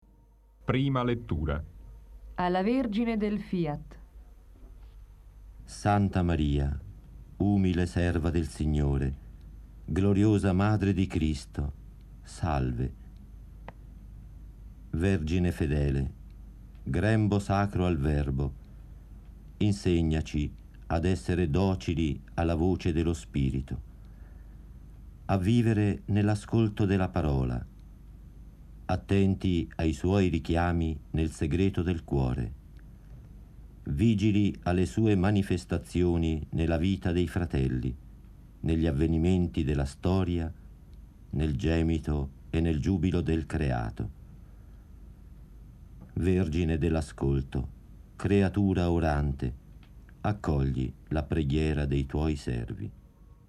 Prima lettura 1,254 Mb   Ascolto